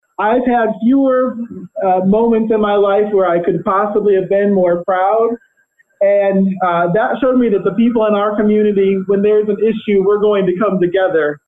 Williams told alderman during Tuesday night’s city council meeting that he saw people of all different groups participating in the march and rally.